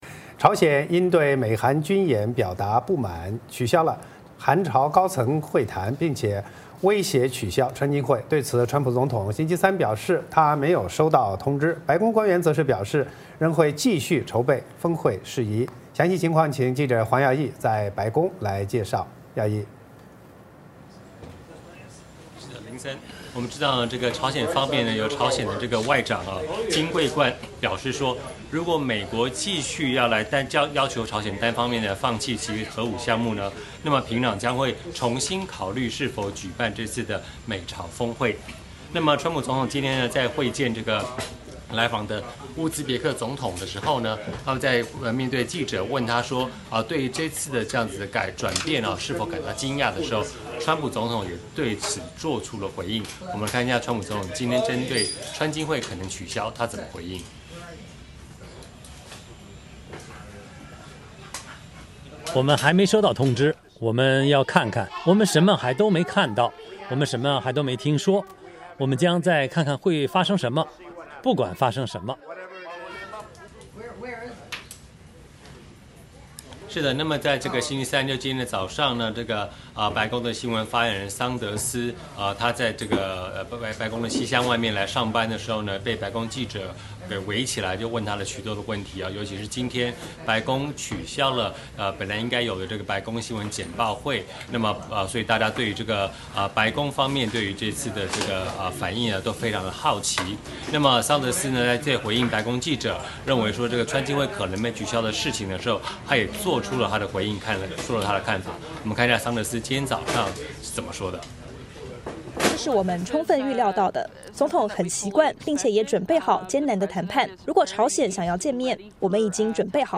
VOA连线
白宫 —